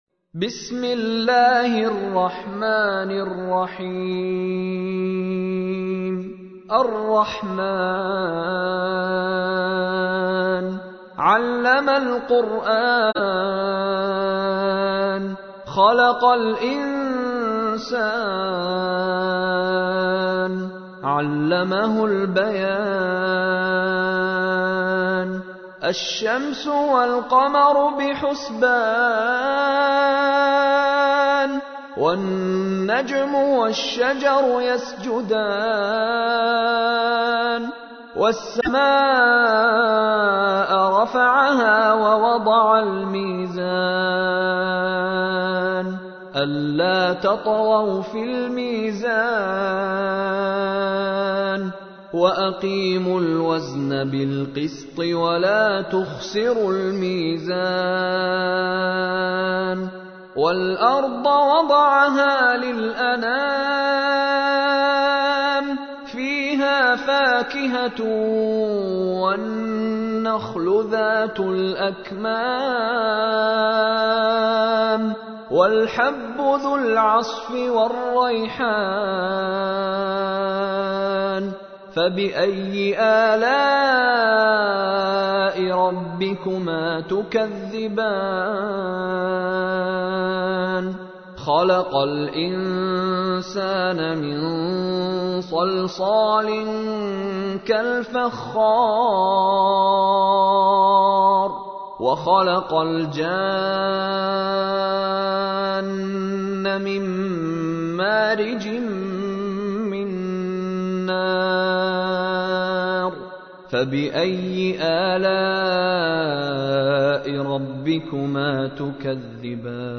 تحميل : 55. سورة الرحمن / القارئ مشاري راشد العفاسي / القرآن الكريم / موقع يا حسين